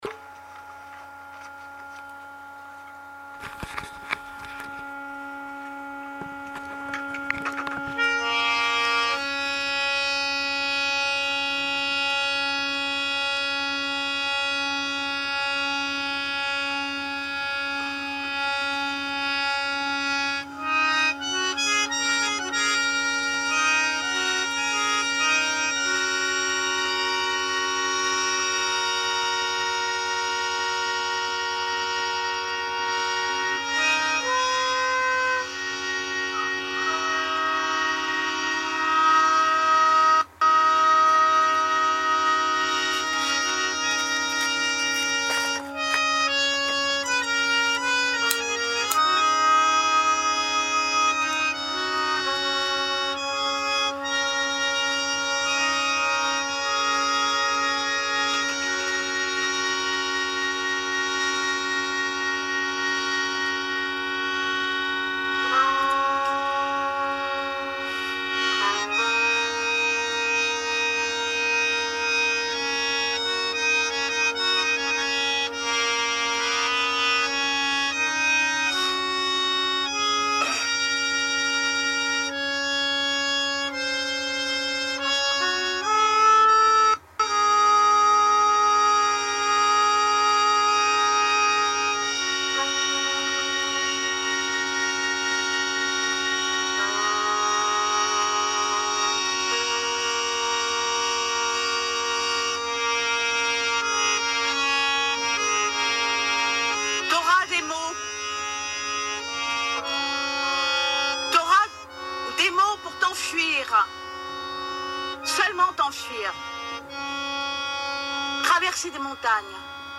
Harmonium et trompette